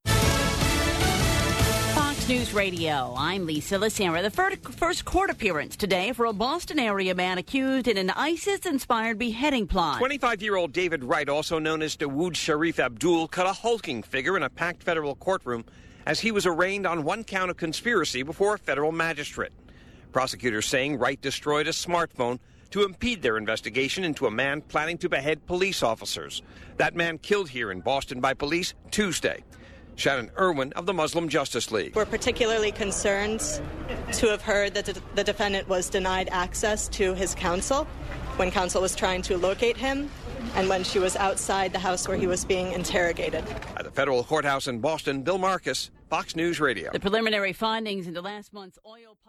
FOX NEWS RADIO, 9PM NEWSCAST –